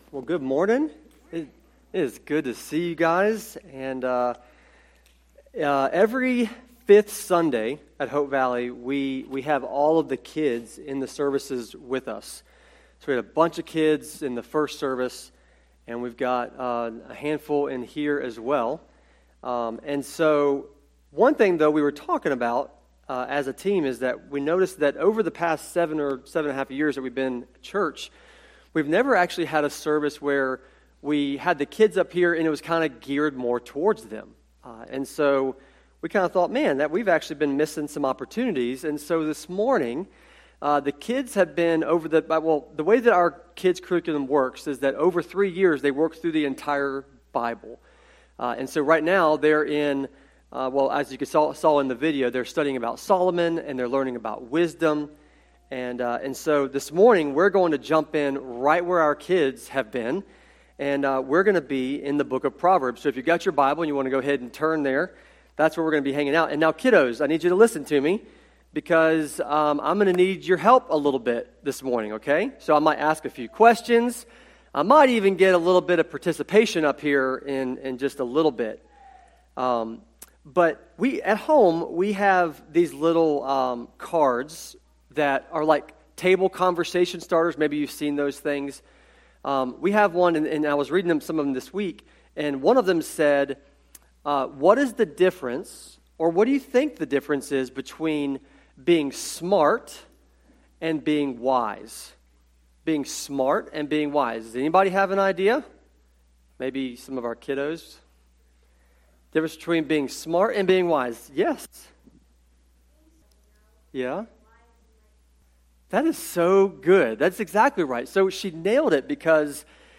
sermon-audio-trimmed-4.mp3